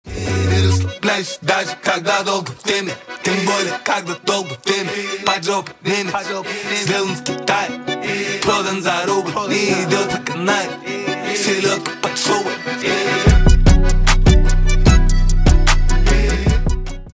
русский рэп
аккордеон , гитара
басы